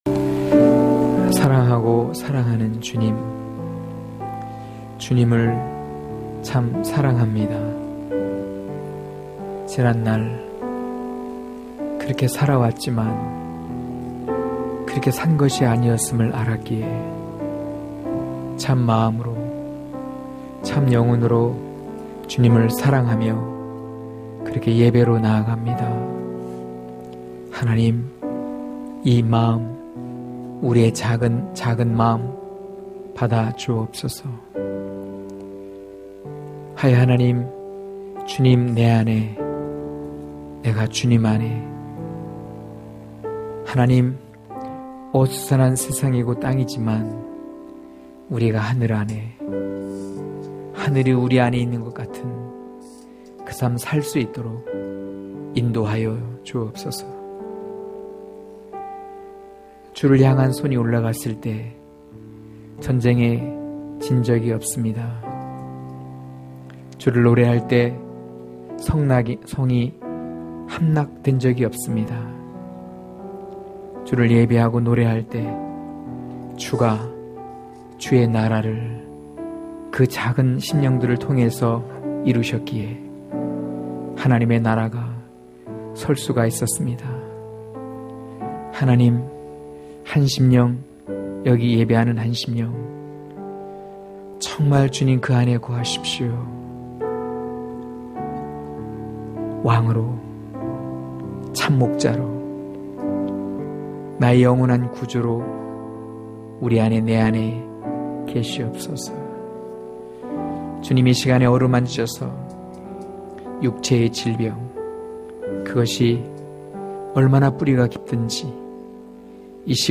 강해설교 - 8.사랑의 정의(요일4장7-18절) (2)